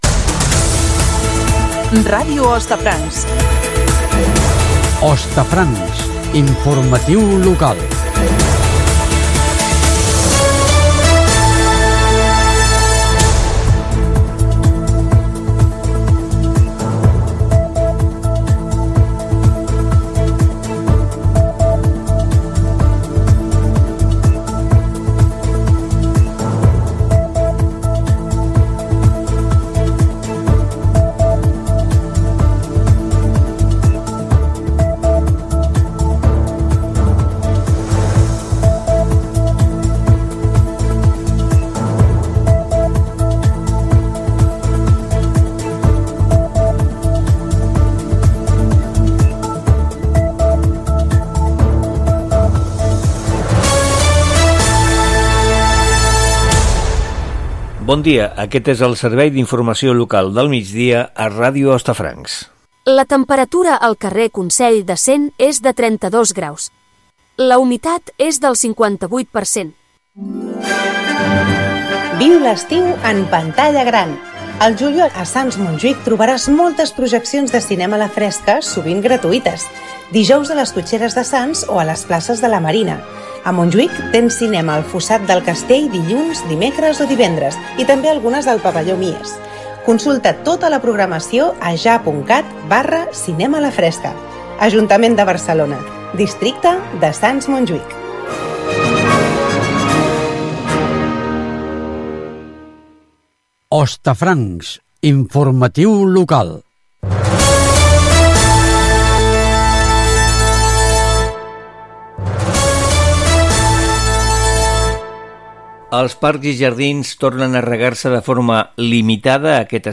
temperatura (amb veu sintètica), promoció de cinema a la fresca, indicatiu, rec als parcs i jardins, convocats els Premis 25 de Novembre
Gènere radiofònic Informatiu